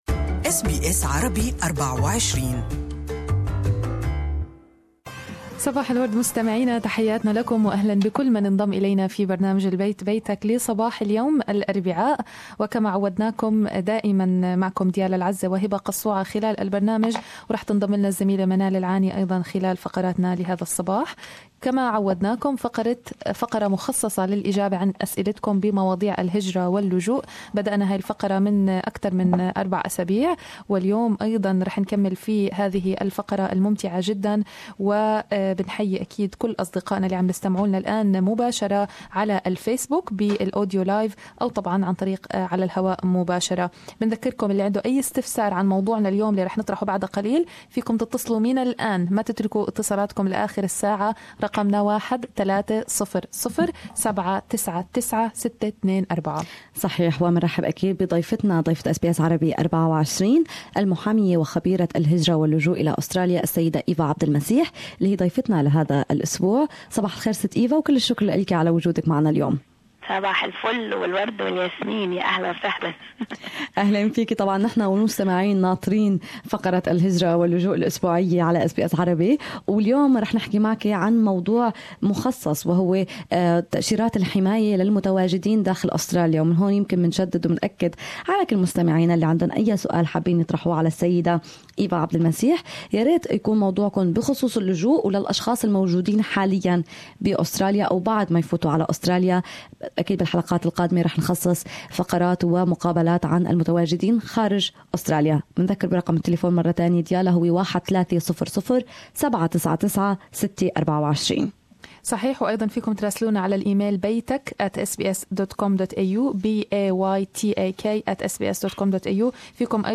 للاستماع للقاء بالكامل يرجى الضغط على المقطع الصوتي أعلى الصفحة استمعوا هنا الى البث المباشر لاذاعتنا و لاذاعة BBC أيضا حمّل تطبيق أس بي أس الجديد على الأندرويد والآيفون للإستماع لبرامجكم المفضلة باللغة العربية.